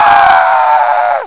AAHHHH!.mp3